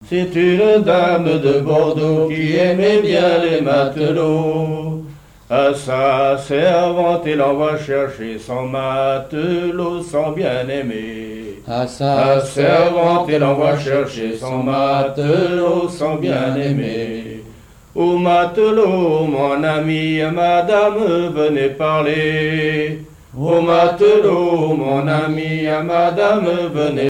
Île-d'Yeu (L')
chansons populaires et traditionnelles maritimes
Pièce musicale inédite